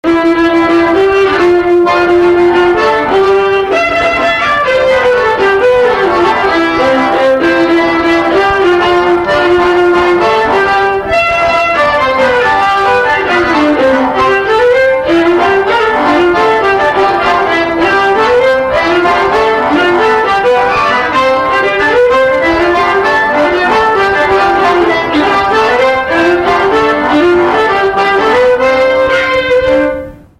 Séga de quadrille
Tampon (Le)
Instrumental
danse : quadrille ; danse : séga ;
Pièce musicale inédite